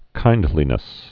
(kīndlē-nĭs)